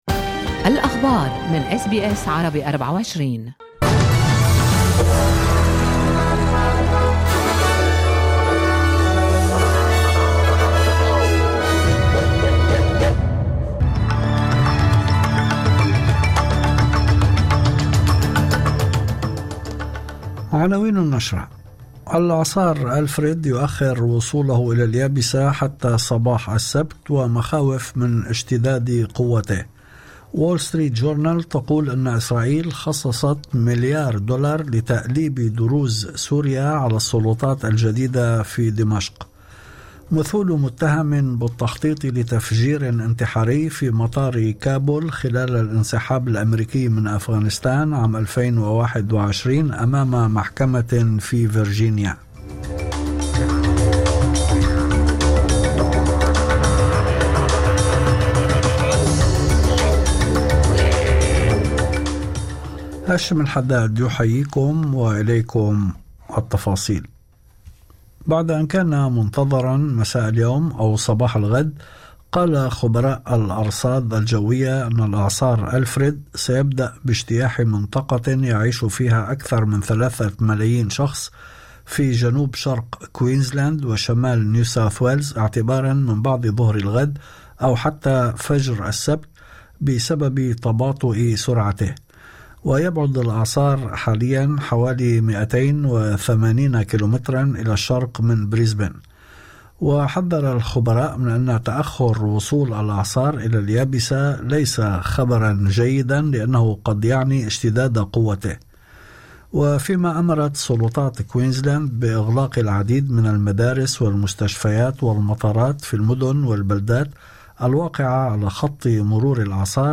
نشرة أخبار المساء 6/3/2025